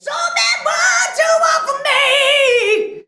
SO BAD BOY.wav